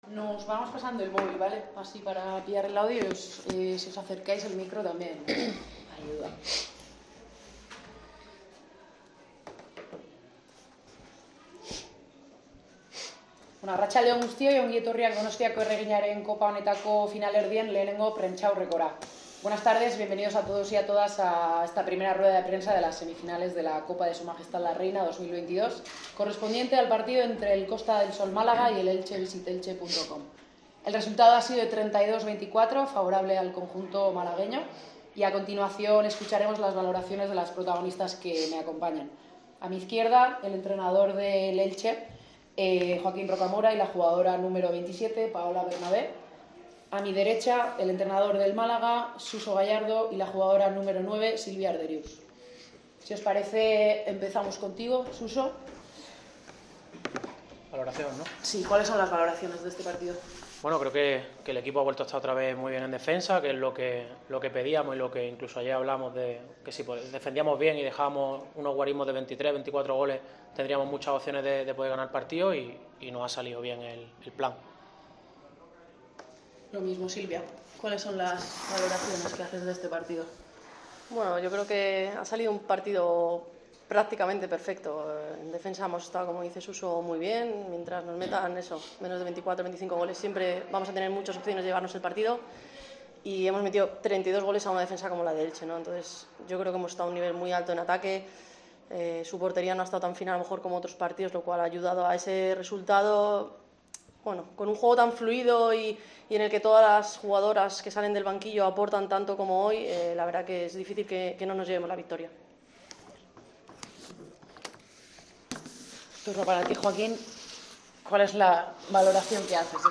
Tras el pase a la final de la Copa de la Reina 2022 de balonmano, varios han sido los componentes del conjunto malagueño que han comparecido ante los medios.